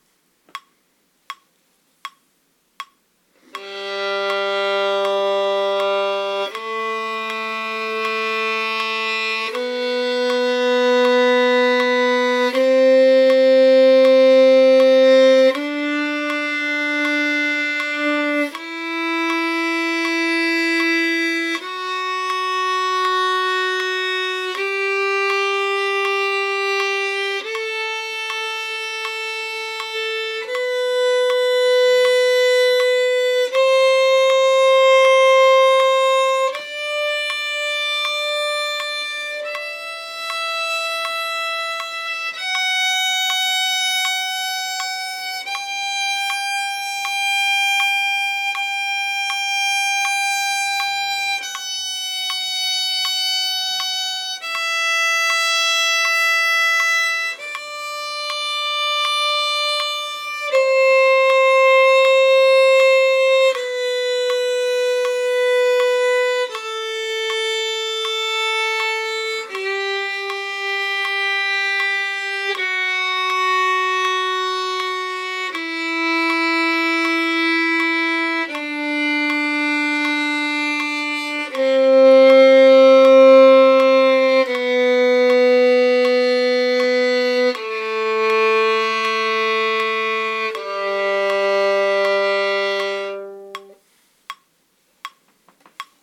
Zu Beginn wird die G-Dur-Tonleiter mit dem ganzen Bogen gestrichen:
Notenbeispiel mit der G-Dur Tonleiter über zwei Oktaven mit ganzen Notenwerten.
G-Dur_ganzer-Bogen.mp3